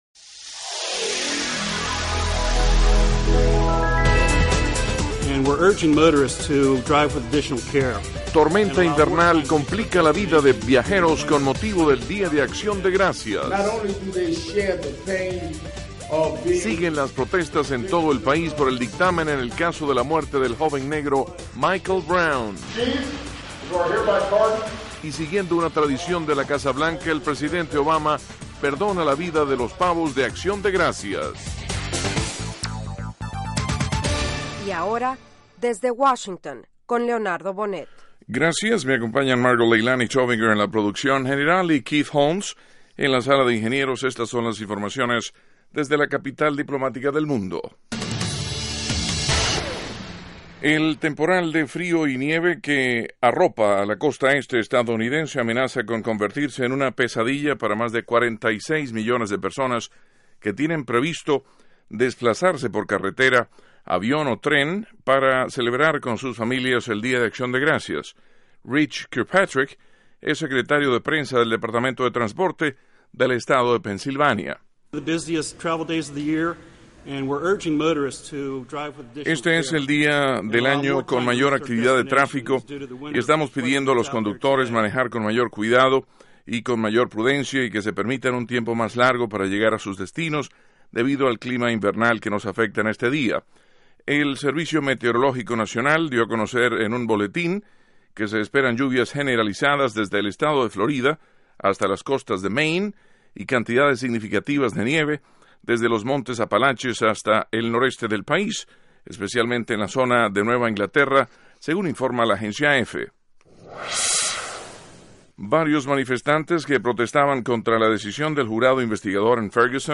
Con entrevistas a líderes políticos, nacionales y extranjeros, Desde Washington ofrece las últimas noticias sobre los acontecimientos que interesan a nuestra audiencia. El programa se transmite de lunes a viernes de 8:00 p.m. a 8:30 p.m. (hora de Washington).